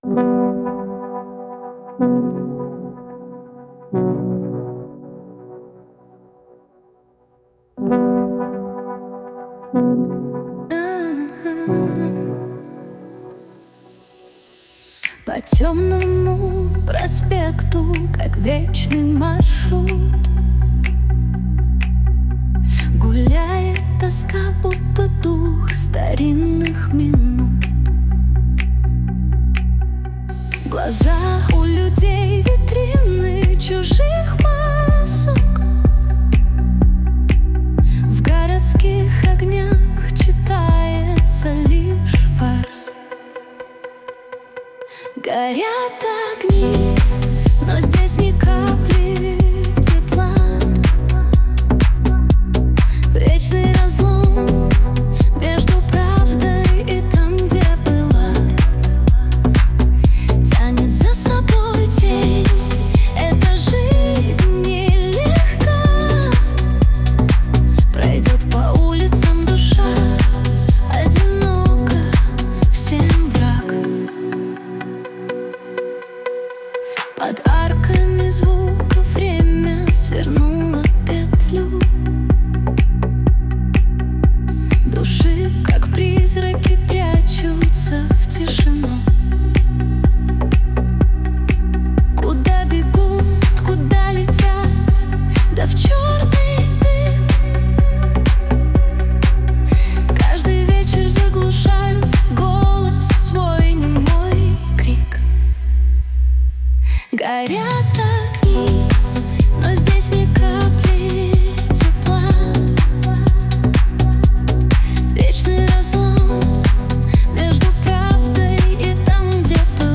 RUS, Romantic, Dance, Funk, Soul, Ambient | 21.03.2025 21:14